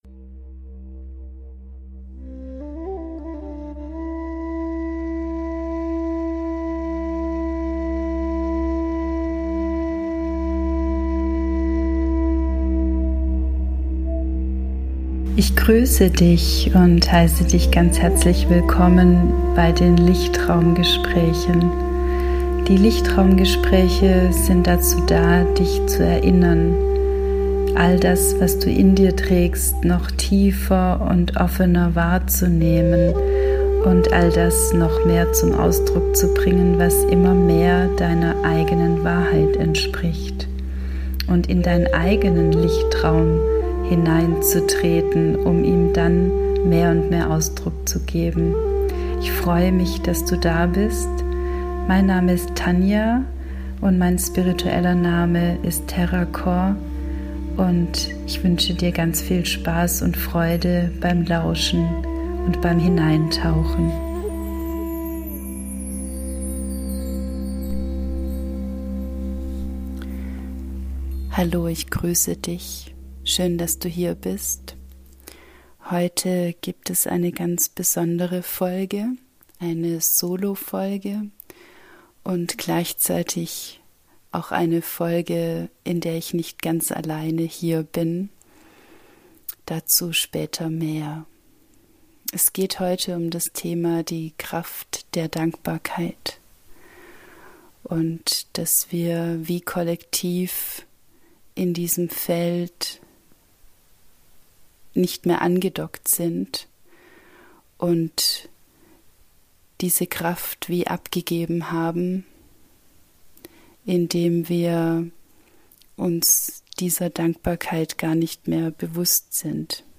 In dieser Solo-Podcastfolge teile ich mit Dir etwas, was mich schon seit längerer Zeit bewegt und sich nun nochmals tiefer in mir bewegt hat.